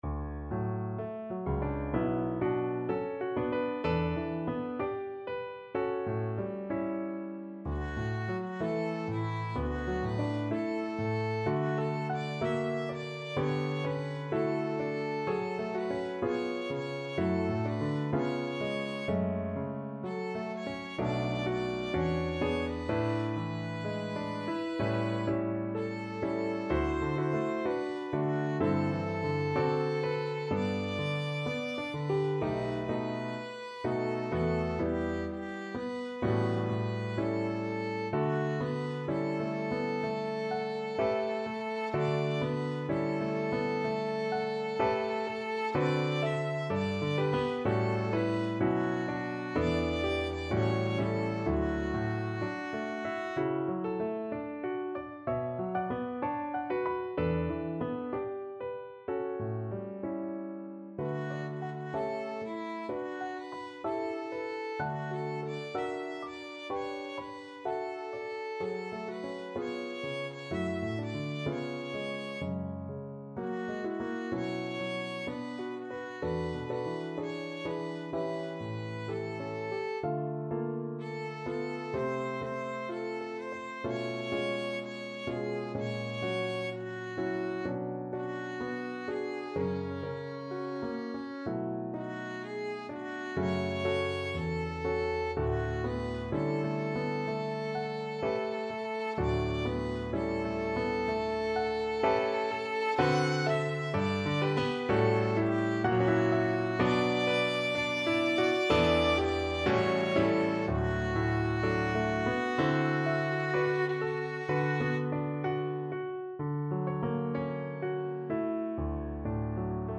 Violin version
4/4 (View more 4/4 Music)
Classical (View more Classical Violin Music)